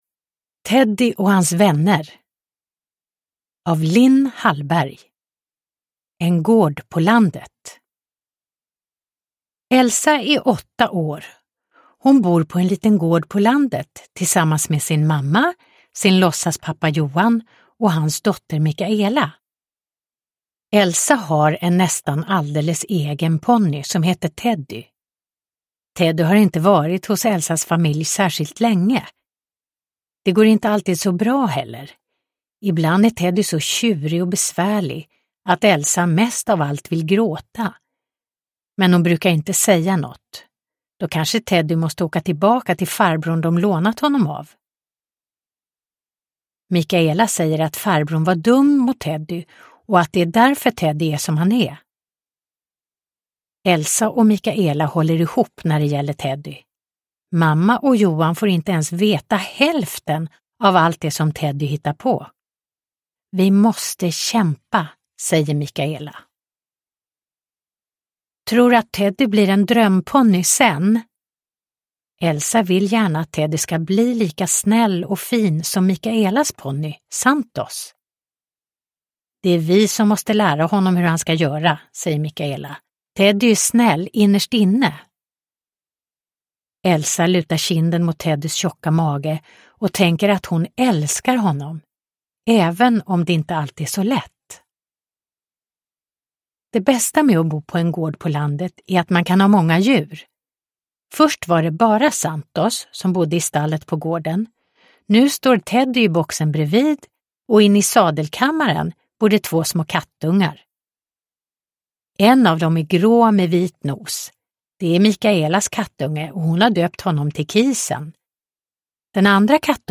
Teddy och hans vänner (ljudbok) av Lin Hallberg